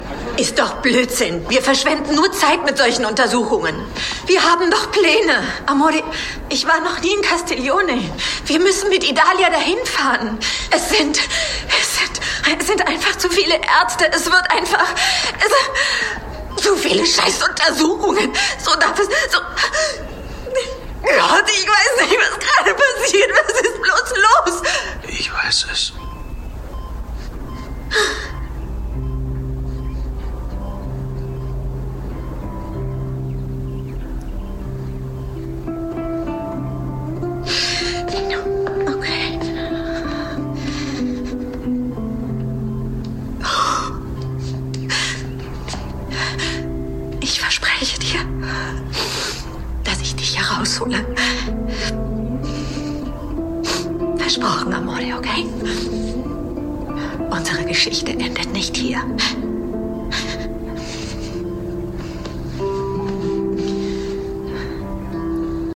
Voice Over Dokumentation